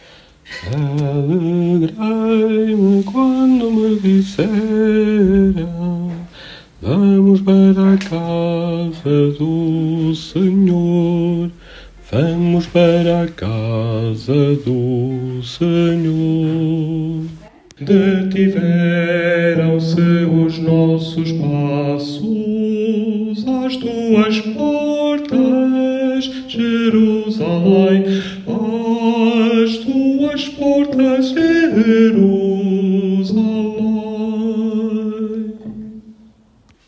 Baixo